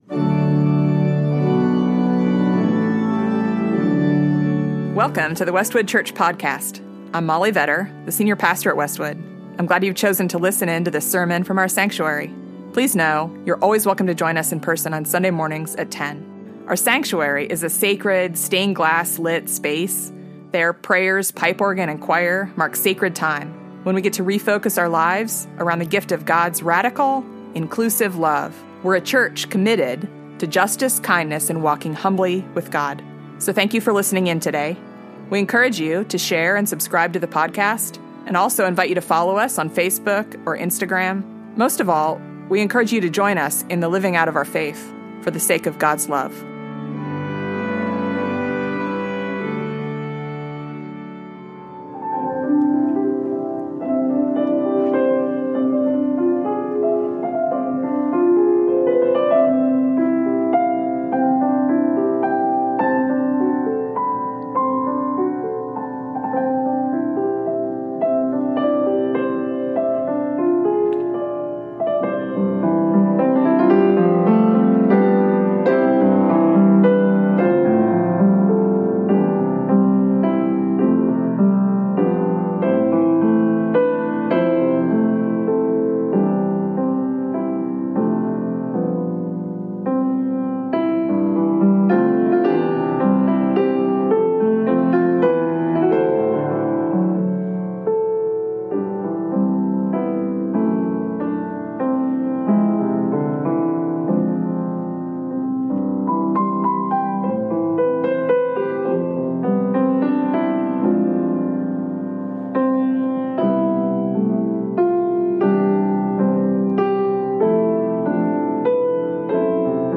In scripture, song and sermon, connect with the power of God’s love to give life in the very midst of death. Join the livestream from our beautiful sanctuary.